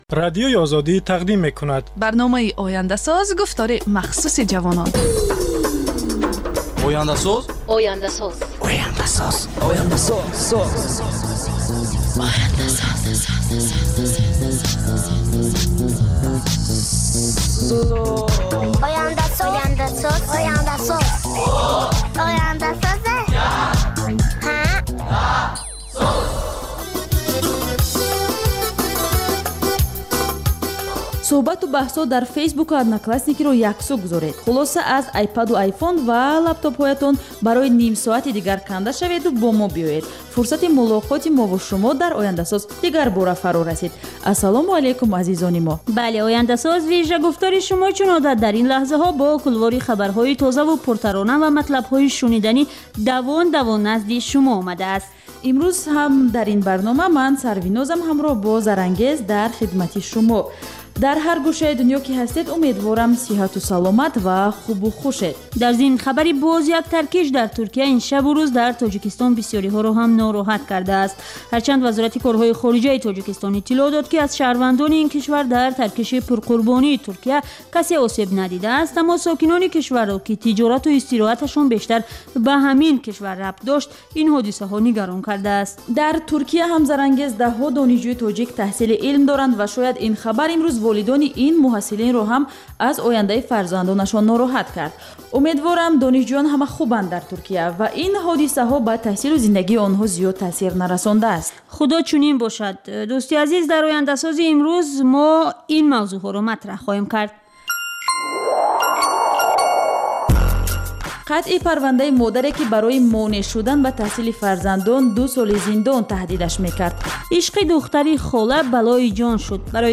Гуфтори вижаи Радиои Озодӣ аз ҳаёти ҷавонони Тоҷикистон ва хориҷ аз он